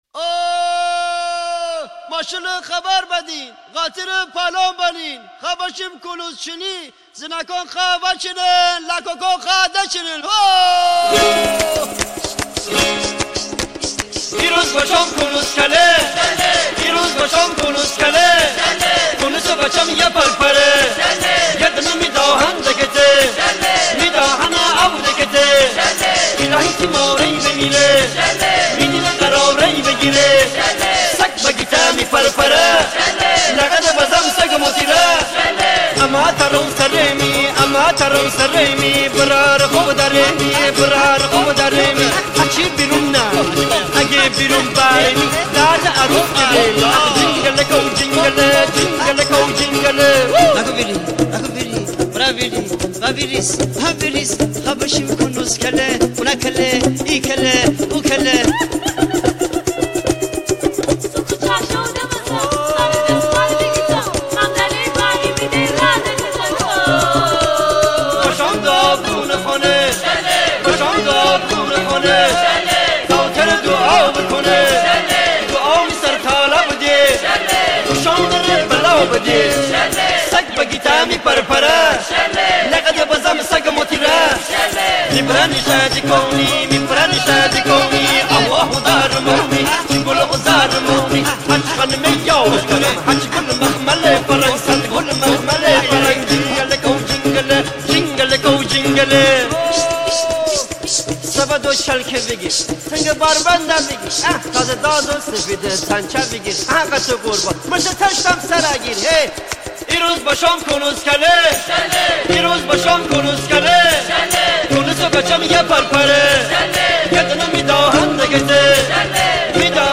آهنگ خیلی شاد گیلانی